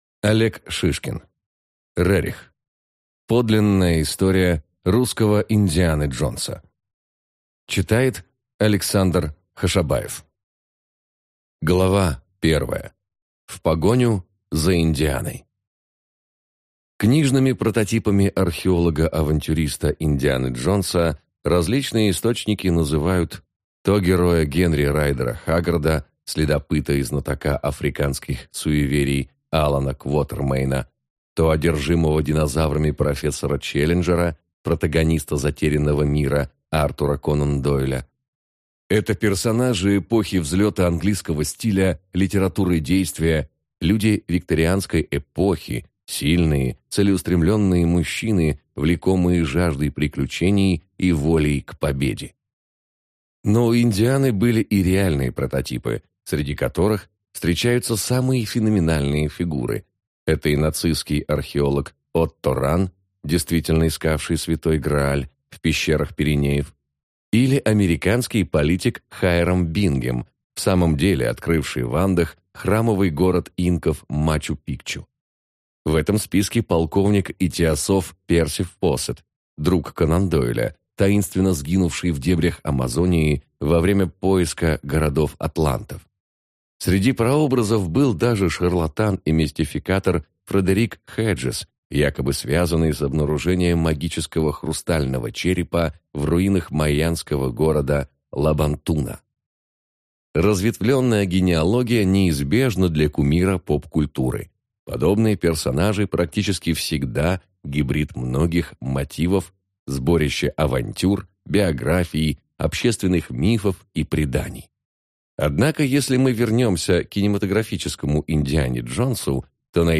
Аудиокнига Рерих. Подлинная история русского Индианы Джонса | Библиотека аудиокниг